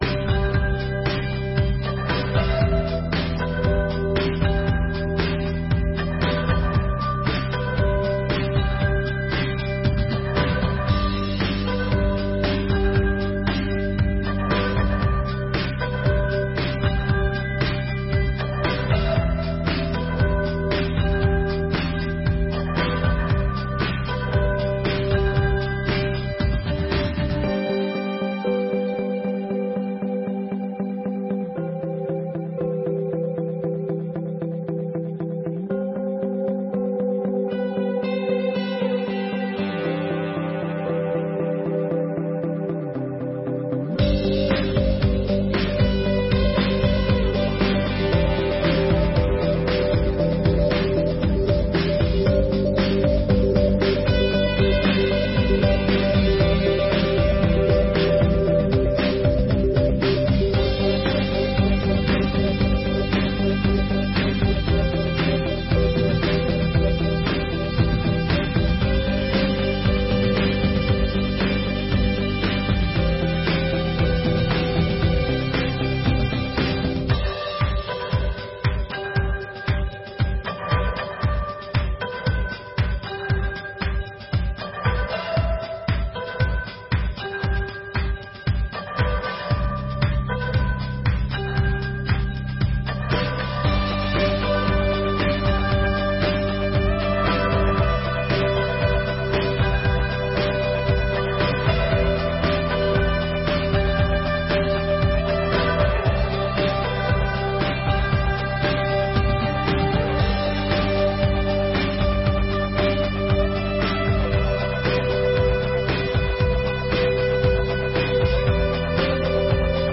1ª Sessão Ordinária de 2022